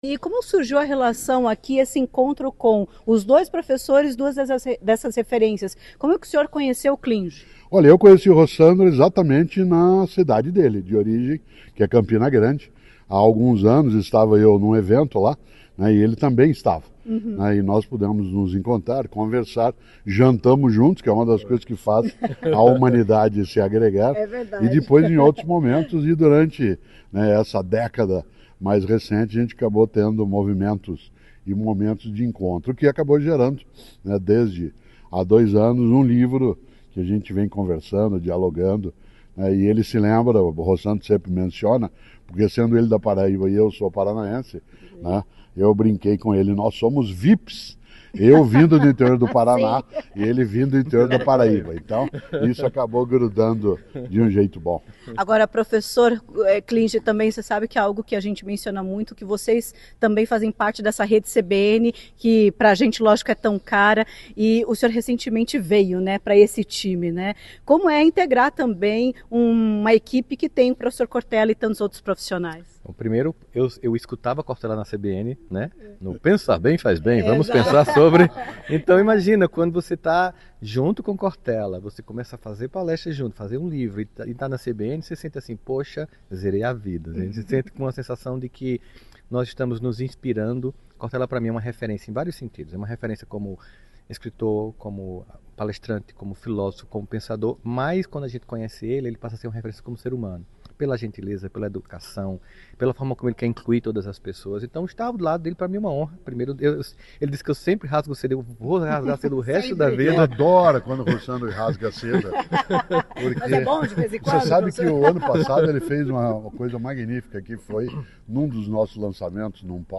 O lançamento contou com uma palestra no Teatro Positivo.